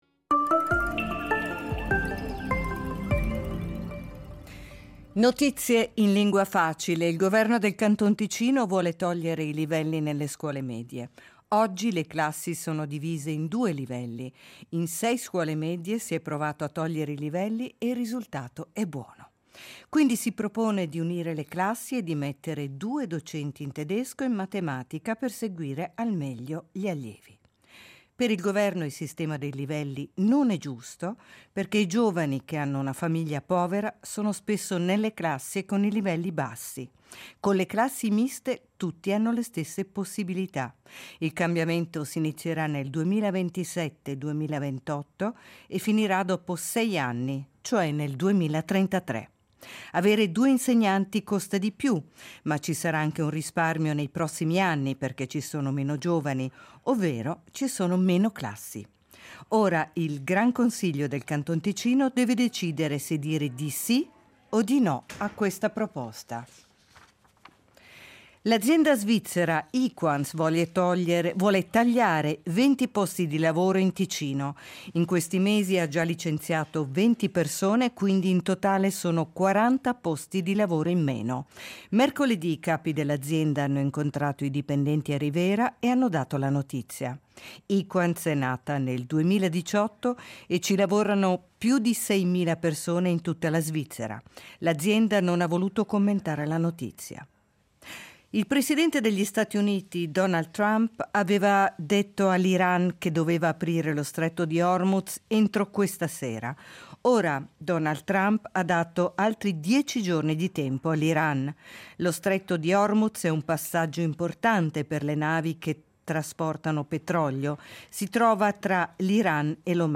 Notizie in lingua facile